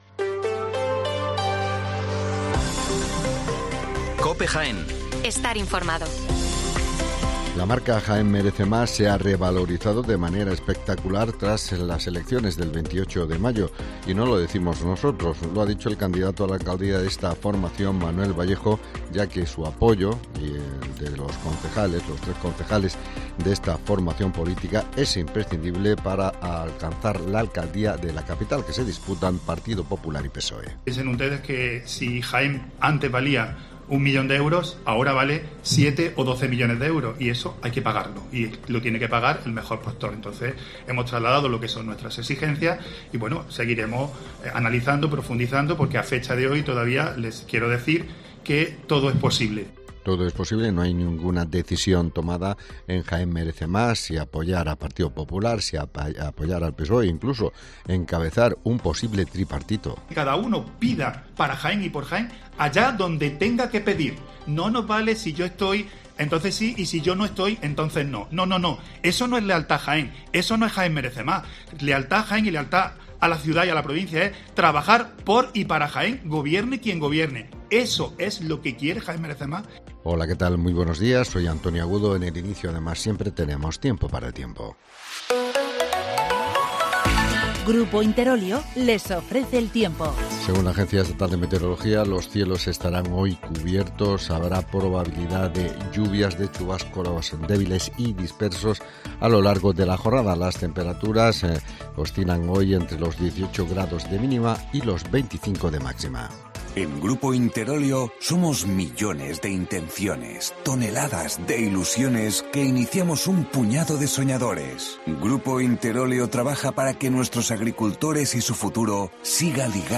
Las noticias locales de las 8'24 del 8 de junio